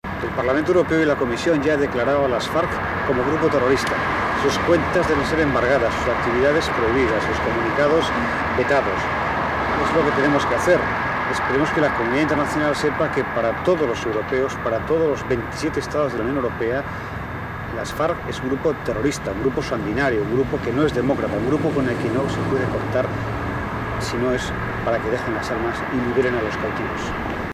Voz del eurodiputado español José Javier Pomés,